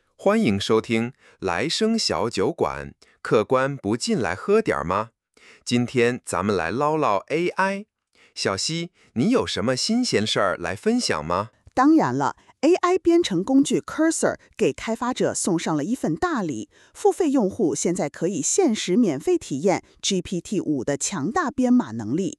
geminiTTS.wav